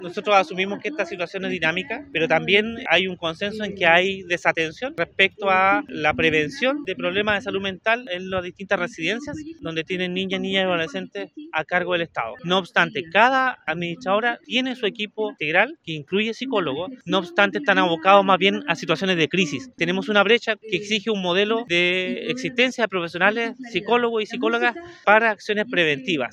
Con una jornada denominada “Unidos por la Salud Mental” que se desarrolló en la Plaza Aníbal Pinto de Temuco, instancia en la que realizaron muestras artísticas y una feria con presentación de stands, se comenzaron a cerrar las actividad del mes de la salud mental.
En ese contexto, el seremi de Salud en La Araucanía, Andrés Cuyul, expresó que en materia de prevención de este tipo de patologías, hay una brecha que se debe acotar en la región.